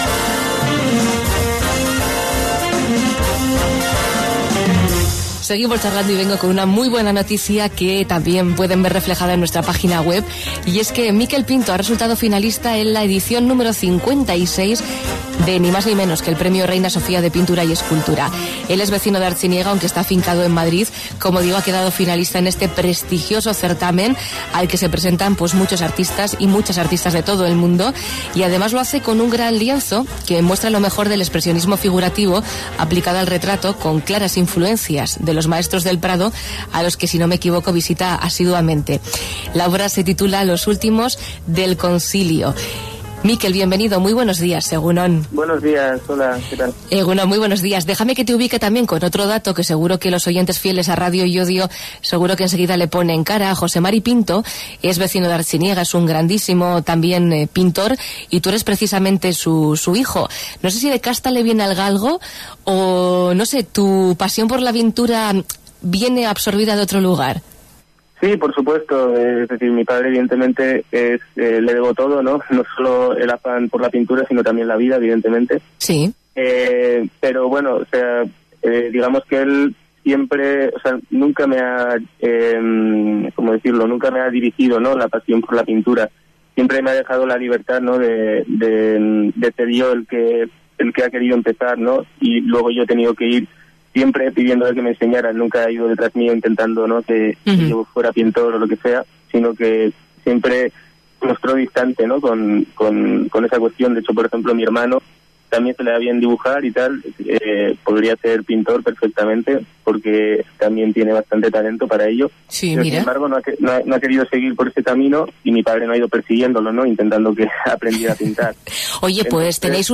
Entrevista en Radio Llodio